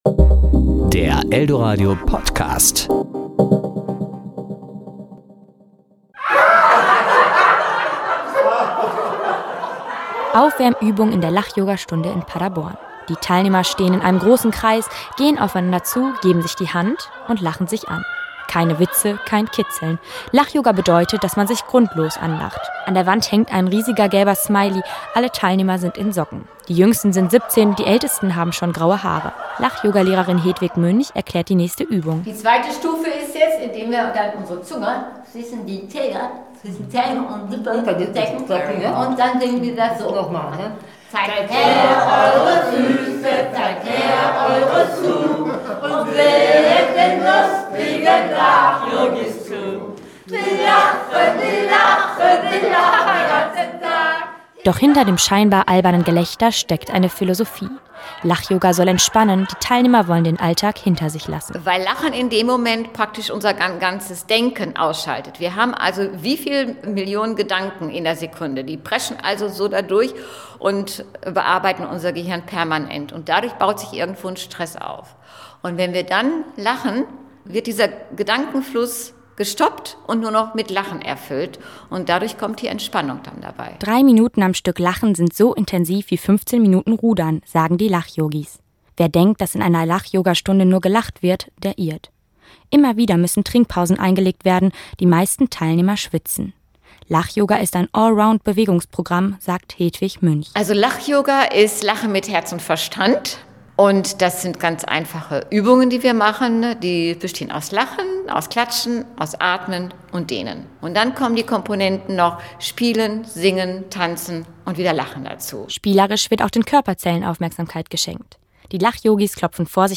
Lachen ist gesund. Und keiner glaubt so sehr daran wie die Lach-Yogis. Super, super, yeah!